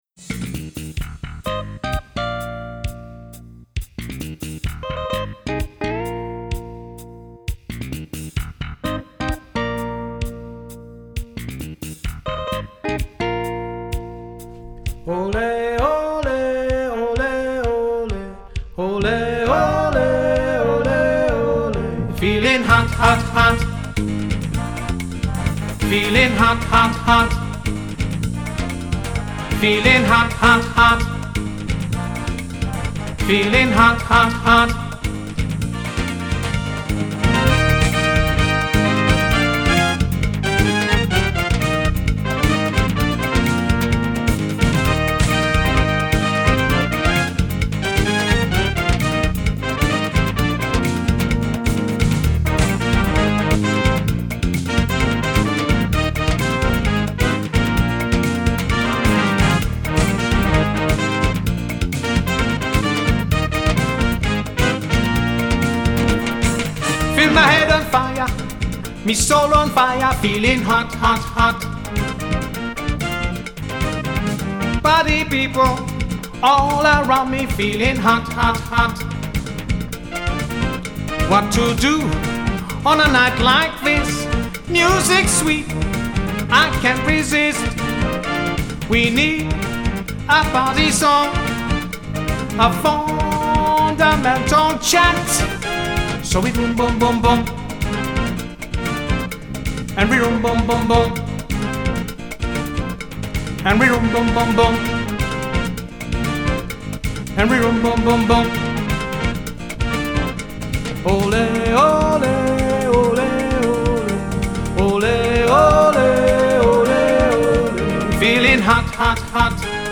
70’s & 80’s / Party Pop / Reggae / Hot Latin / Disco